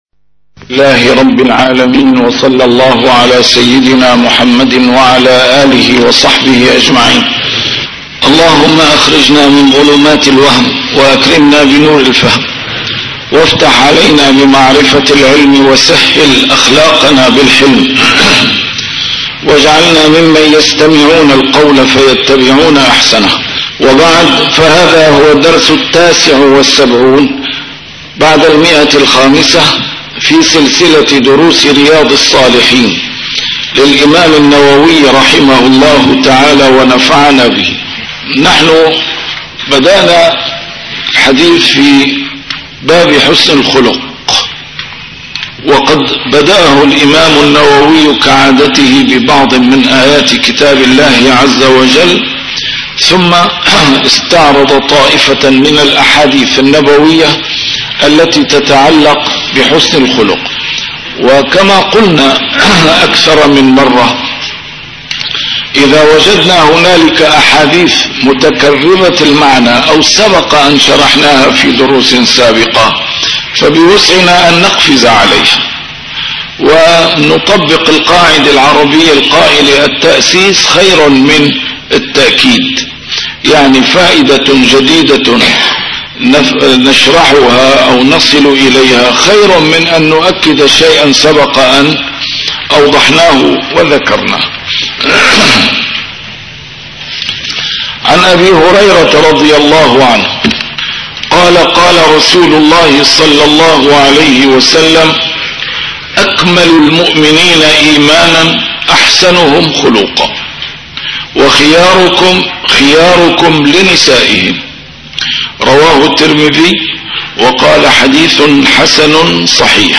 A MARTYR SCHOLAR: IMAM MUHAMMAD SAEED RAMADAN AL-BOUTI - الدروس العلمية - شرح كتاب رياض الصالحين - 579- شرح رياض الصالحين: حسن الخلق